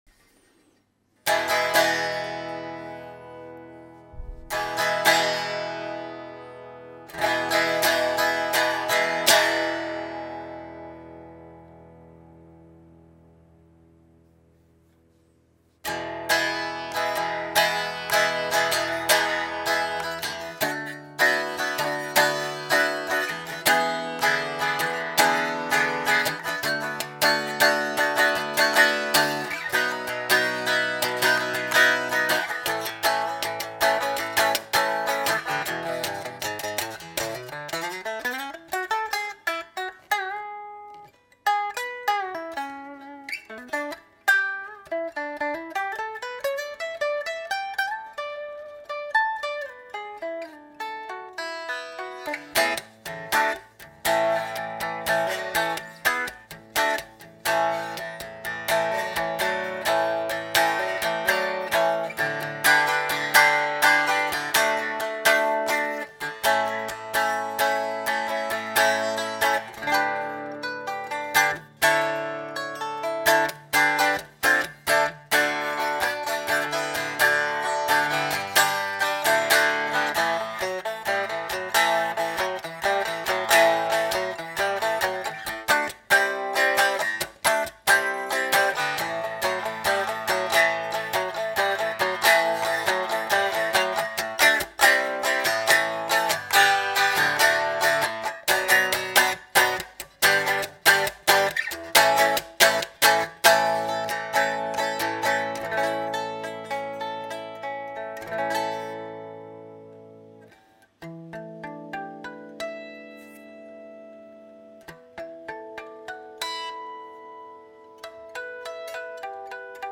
We also made an actual unamplified acoustic recording of this vibrant beauty:
unamplified-1965-SG-Standard.mp3